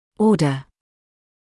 [‘ɔːdə][‘оːдэ]порядок, последовательно, очередность; приказ